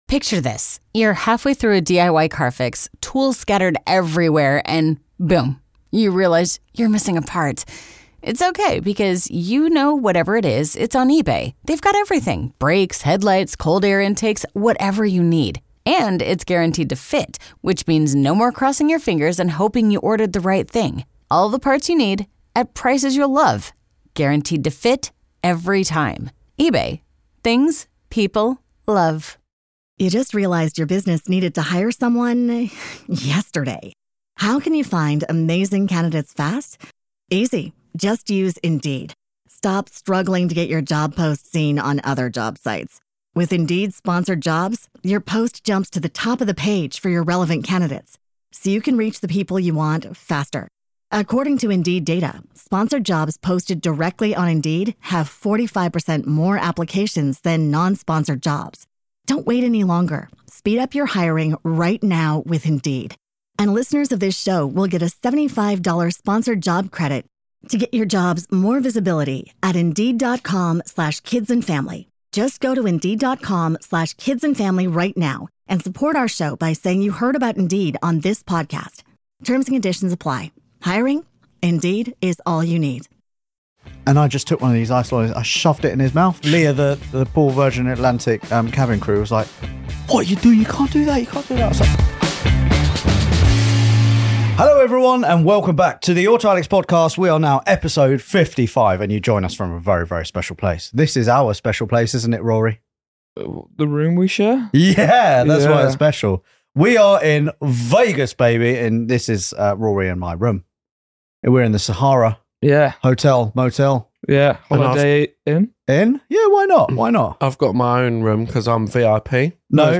This week, we are live in Las Vegas for the world’s biggest car show, an amazing road trip to the Grand Canyon and stories from a VERY eventful flight to the US!